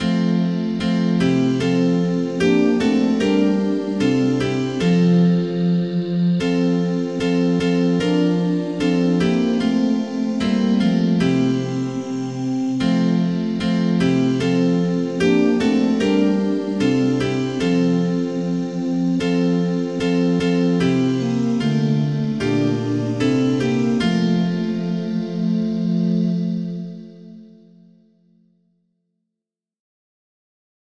Believers in the Church Age can also sing a hymn after eating the bread and drinking the cup.
The first file has a higher-quality sound.